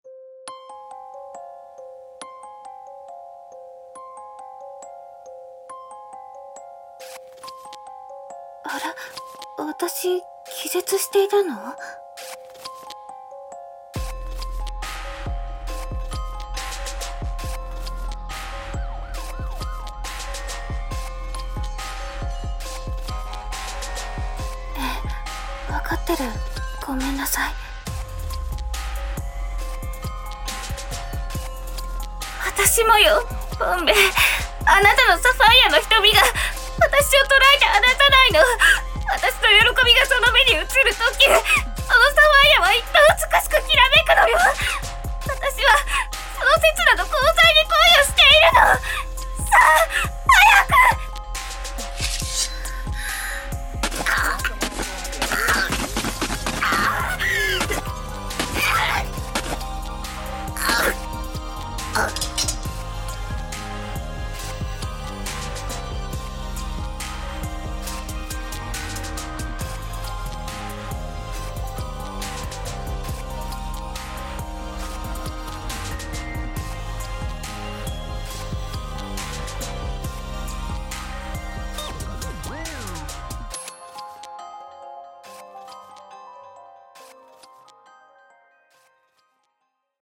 狂人は瞳に恋をする【2人声劇】